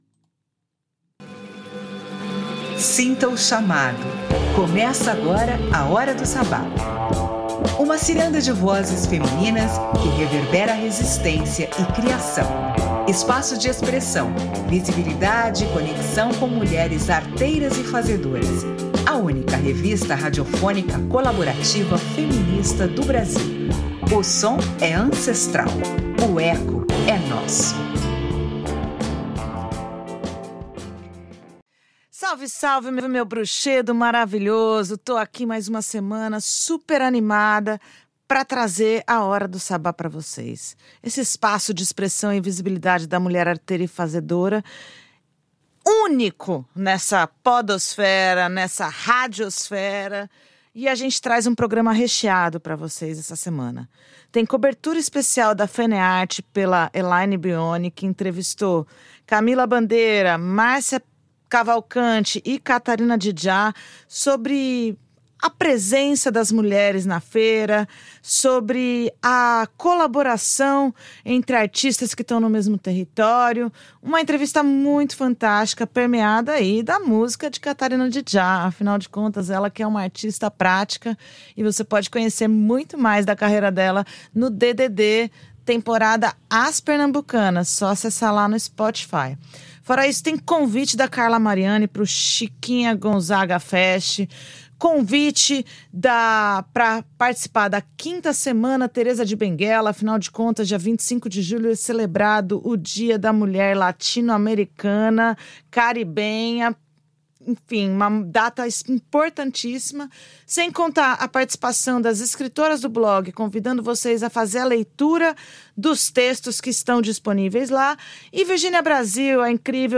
Nesta edição especial do Hora do Sabbat, percorremos os corredores da maior feira de arte popular da América Latina para escutar mulheres que fazem da criação artesanal um campo de permanência, colaboração e reinvenção.